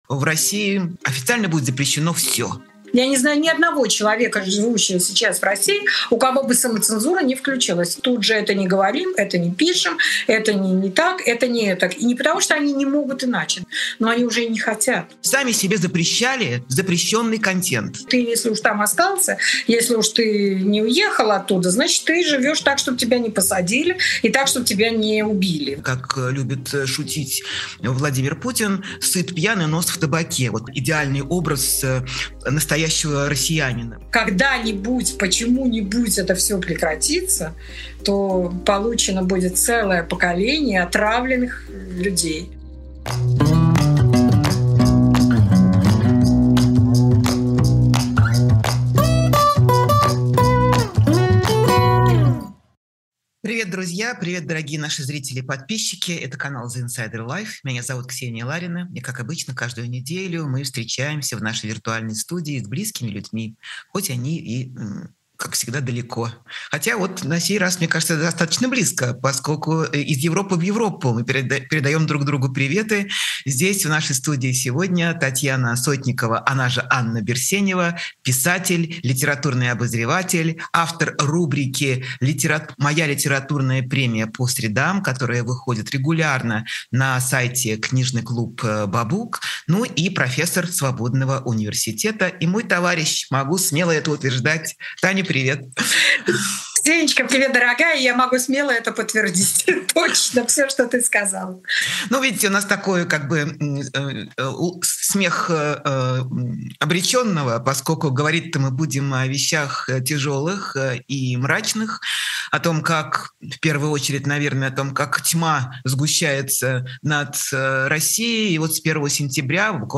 Эфир ведёт Ксения Ларина
Гость — писательница Татьяна Сотникова (Анна Берсенева).